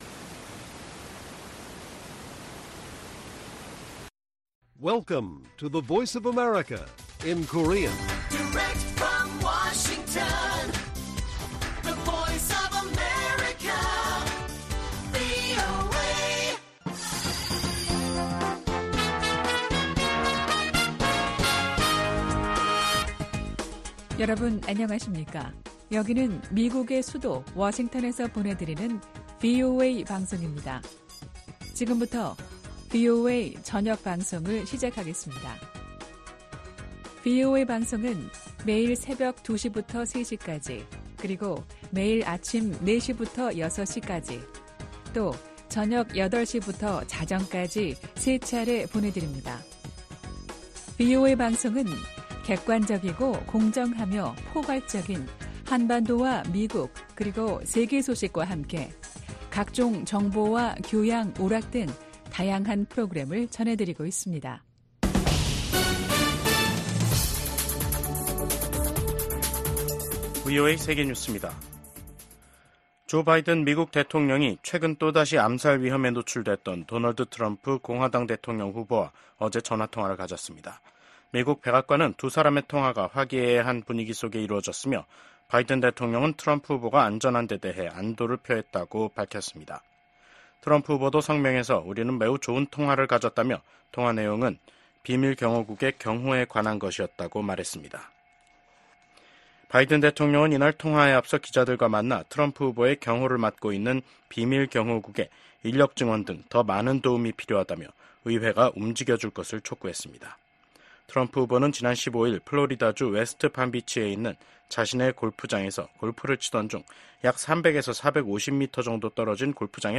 VOA 한국어 간판 뉴스 프로그램 '뉴스 투데이', 2024년 9월 17일 1부 방송입니다. 스웨덴이 신종 코로나에 따른 북한의 국경 봉쇄 조치 이후 서방 국가로는 처음으로 외교관들을 북한에 복귀시켰습니다. 국제원자력기구(IAEA) 총회가 개막한 가운데 북한의 지속적인 핵 개발은 명백한 유엔 안보리 결의 위반이라고 IAEA 사무총장이 지적했습니다.